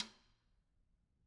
Snare2-taps_v2_rr2_Sum.wav